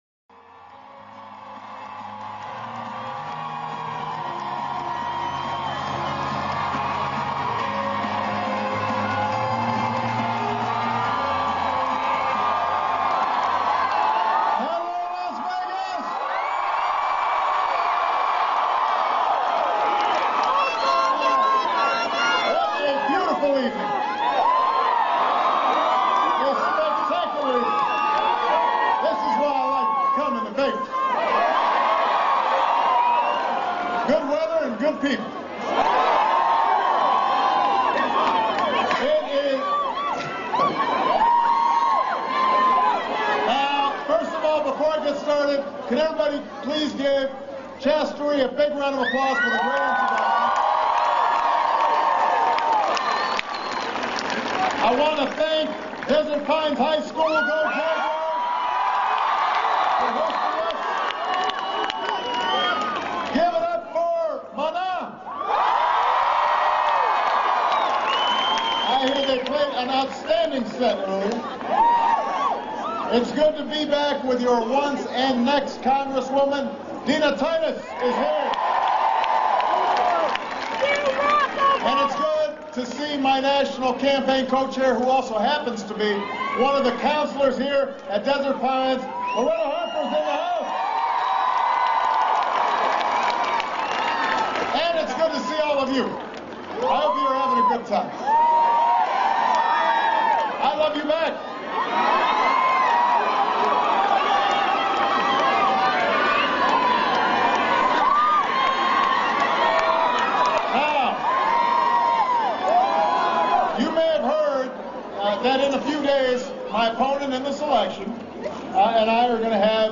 President Obama campaigns in Las Vegas, Nevada. He speaks about a number of issues and talks about the upcoming presidential debates with Mitt Romney.
He emphasizes his own policies on energy, education and creating opportunity. Significant echo from the public address system is heard through most of the recording.